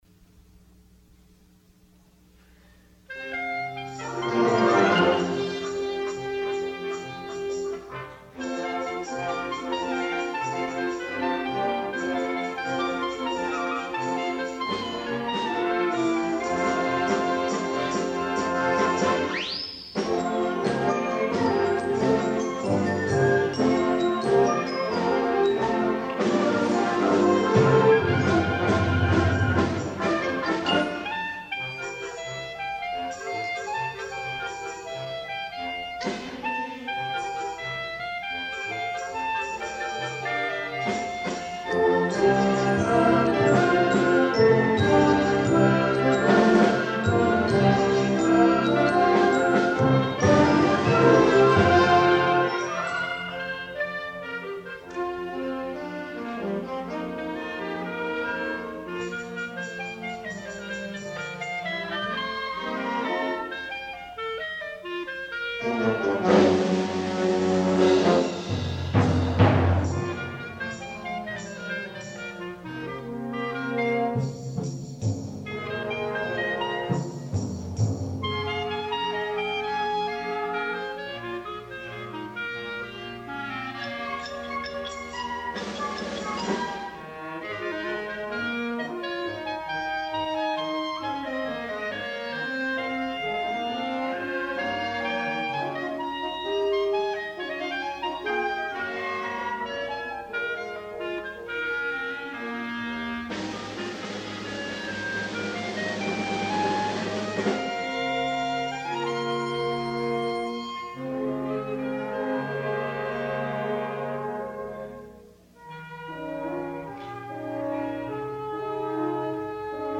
Voicing: Bb Clarinet w/ Band